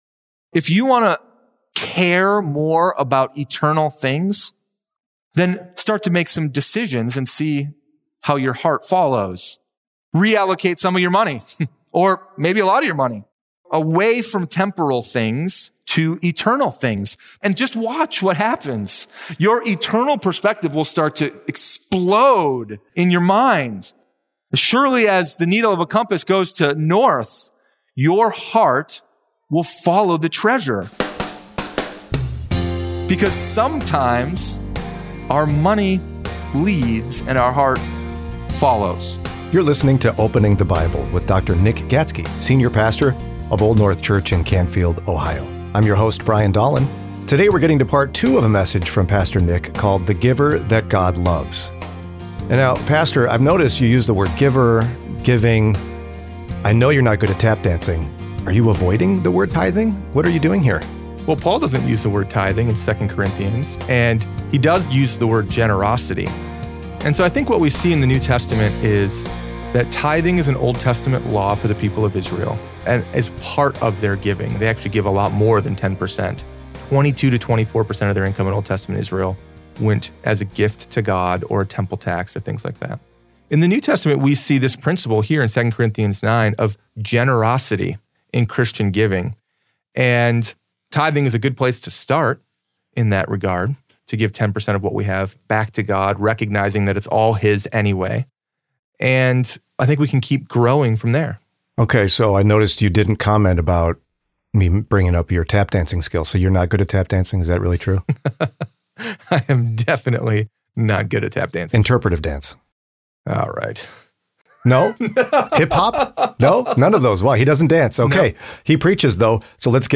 Sermon Library – Old North Church